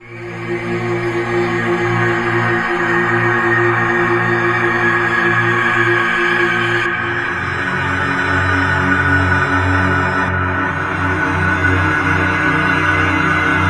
古代垫环路
Tag: 70 bpm Ambient Loops Pad Loops 1.15 MB wav Key : A